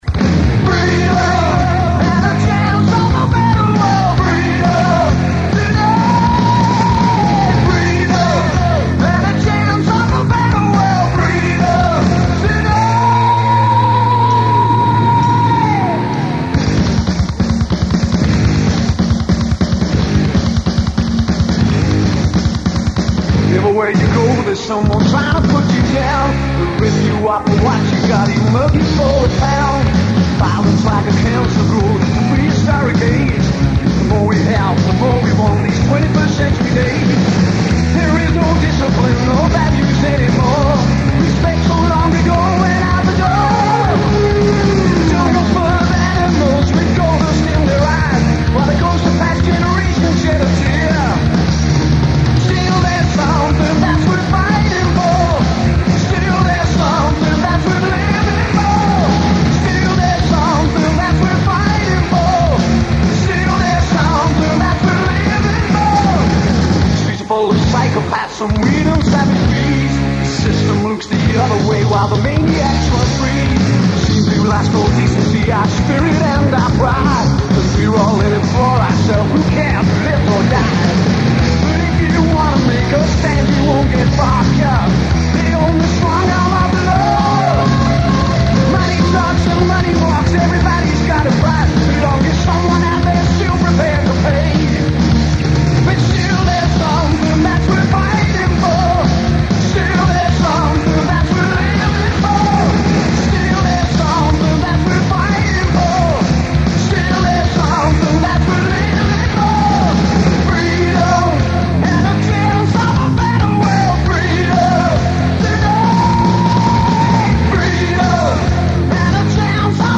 Òåìà: Âîïðîñ çíàþùèì õýâè-ìåòàëë 80-õ
Êà÷åñòâî ïëîõîå, ïîòîìó ÷òî îöèôðîâàíî ñ àóäèîêàññåòû.